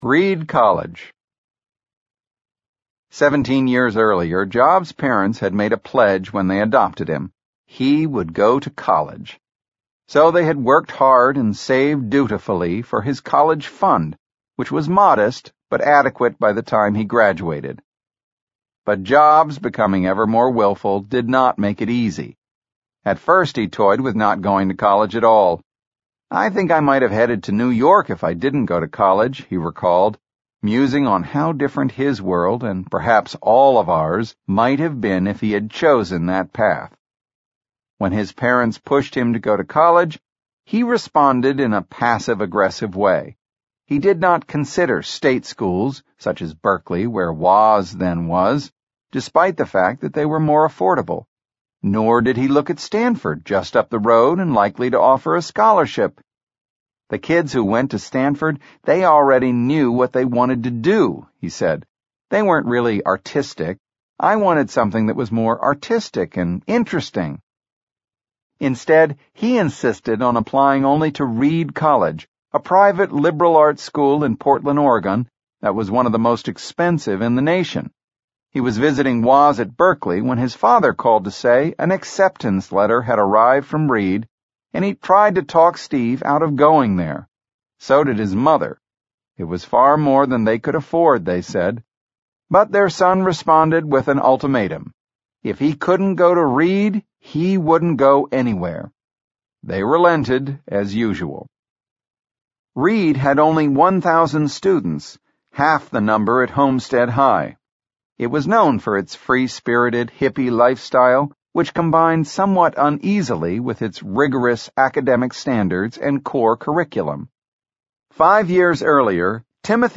在线英语听力室乔布斯传 第30期:非里德学院不读(1)的听力文件下载,《乔布斯传》双语有声读物栏目，通过英语音频MP3和中英双语字幕，来帮助英语学习者提高英语听说能力。
本栏目纯正的英语发音，以及完整的传记内容，详细描述了乔布斯的一生，是学习英语的必备材料。